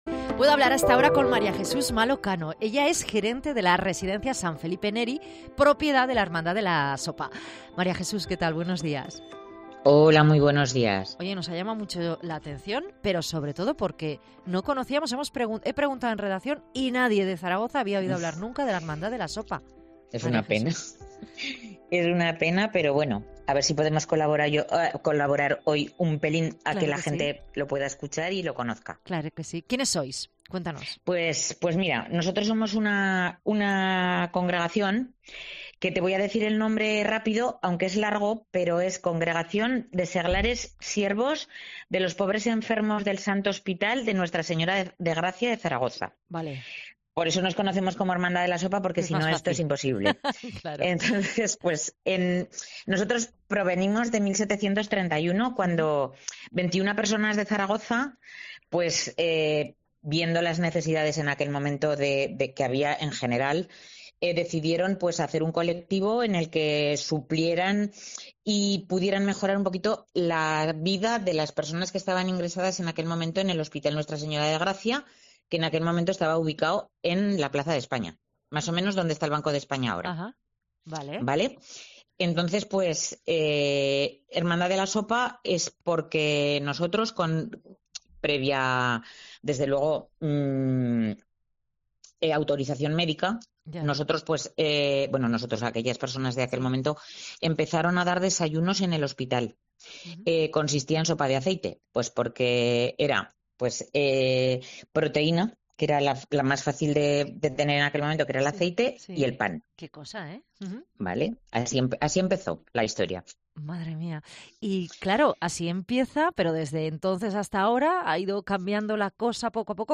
Entrevista sobre la historia de la Hermandad de la Sopa